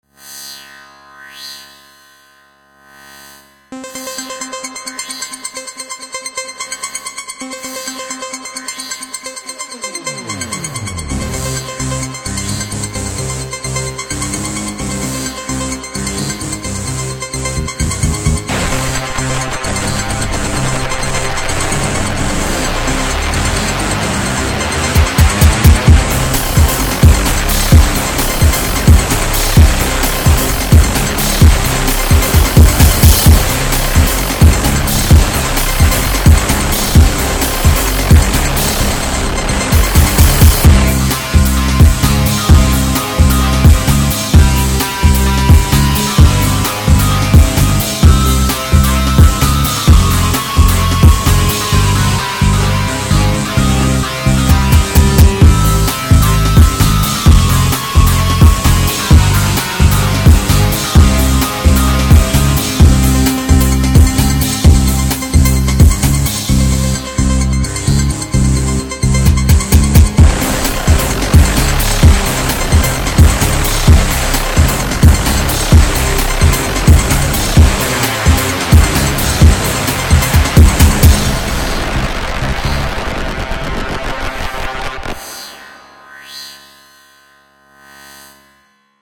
супер-мега ремикс
написаного в стиле Industro.
Industrial - forever!!!